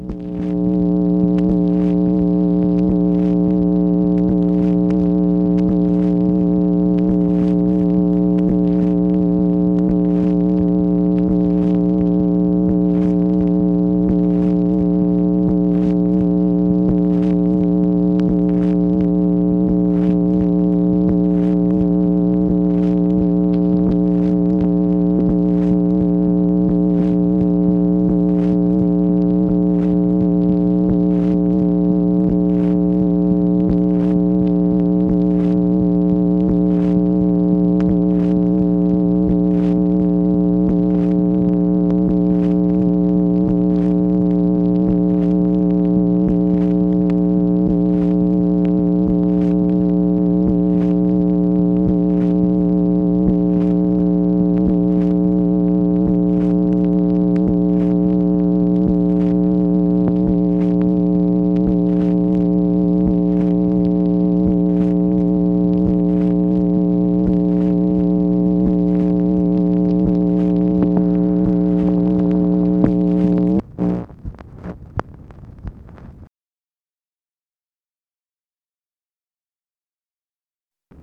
MACHINE NOISE, October 1, 1965
Secret White House Tapes | Lyndon B. Johnson Presidency